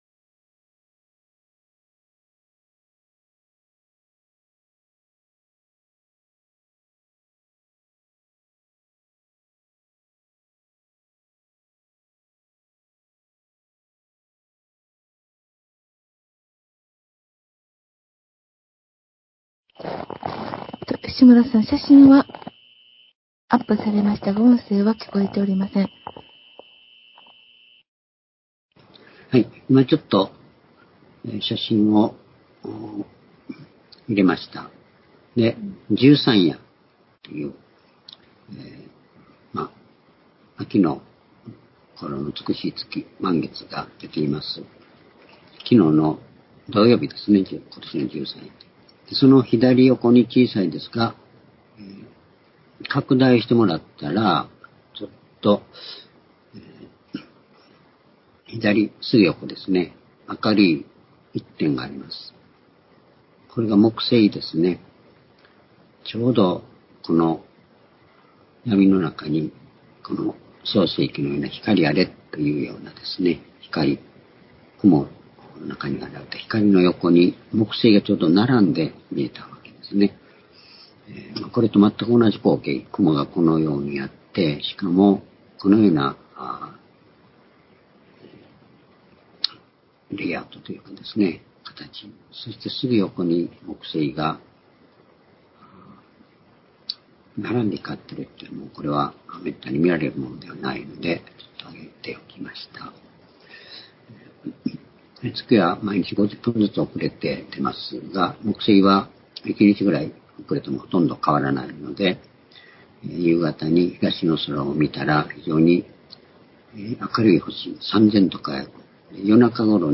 主日礼拝日時 ２０２２年10月9日（主日礼拝） 聖書講話箇所 「世の終わりの復活から、信じることによる永遠の命」 ヨハネ６章36節～40節 ※視聴できない場合は をクリックしてください。